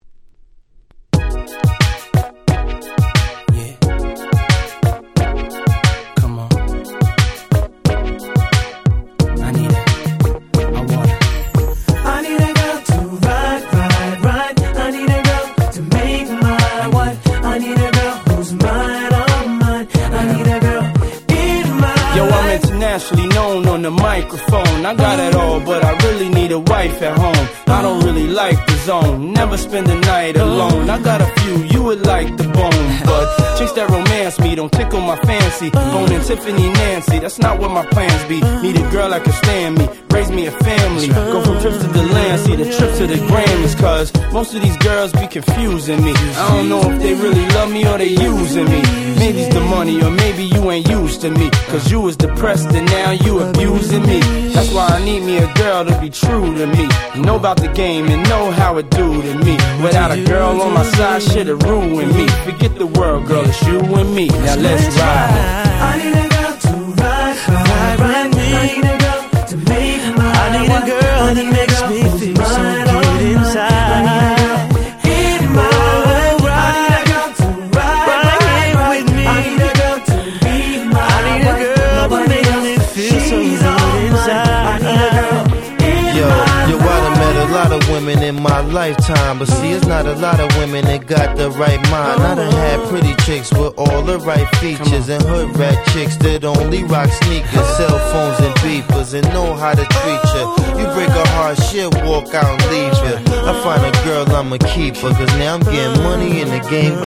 キャッチー系